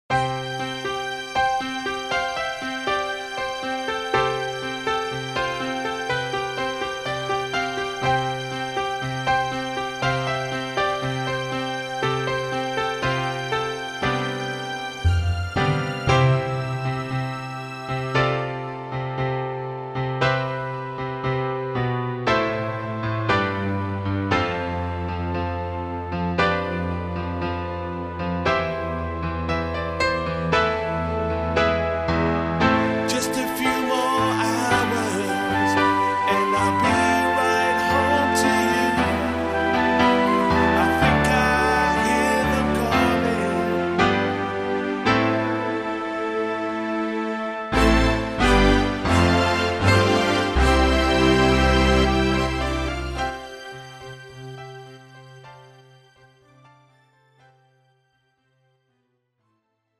MR 반주입니다.